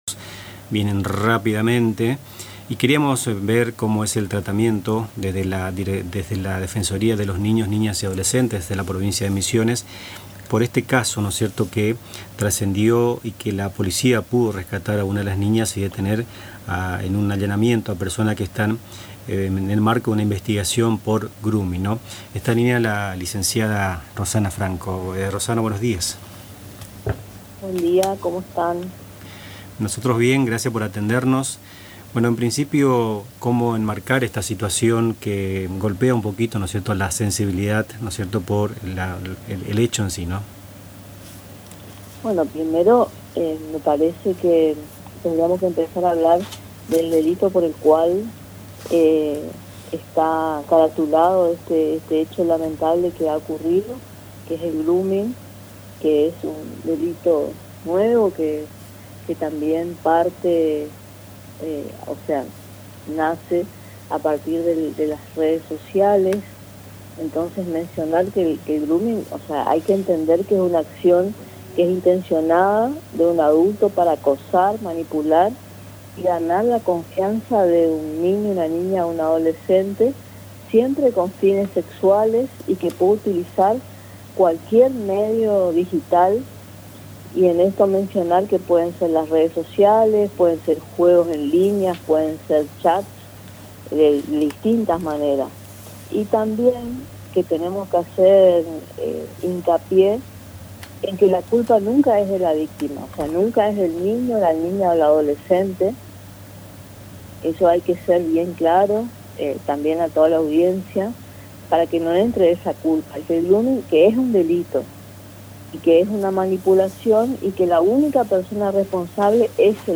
La provincia de Misiones se encuentra conmocionada por un nuevo caso de grooming, delito que implica el acoso y la manipulación de menores a través de medios digitales con fines sexuales. En diálogo con Nuestras Mañanas, la defensora Rosana Franco explicó los alcances del delito, las herramientas de prevención disponibles y el acompañamiento que se brinda a las víctimas.